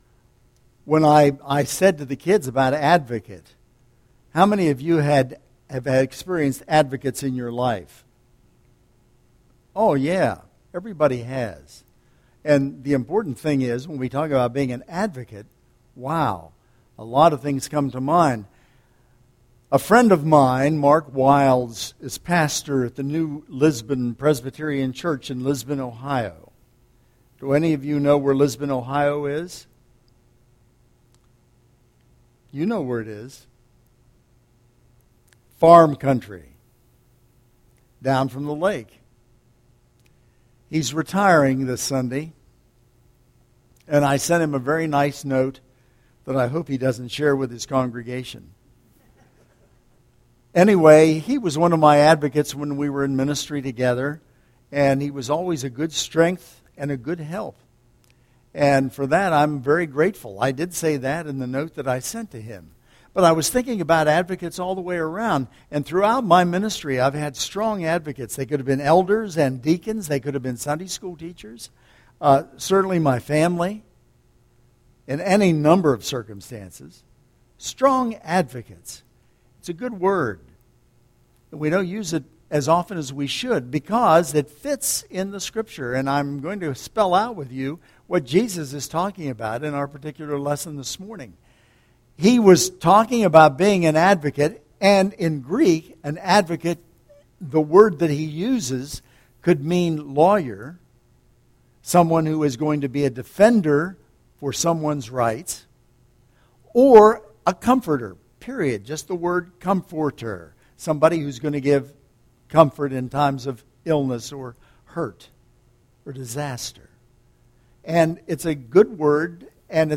Sermon Tags